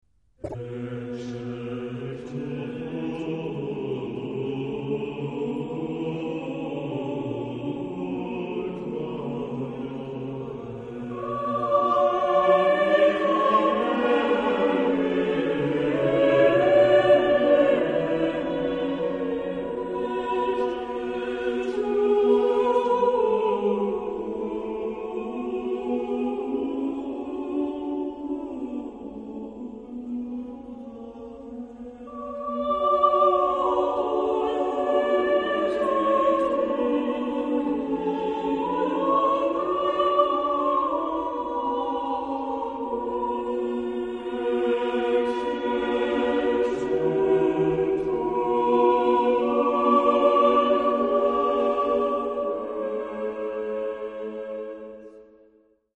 Genre-Style-Forme : Sacré ; Renaissance ; Motet
Type de choeur : SATB  (4 voix mixtes )
Tonalité : la majeur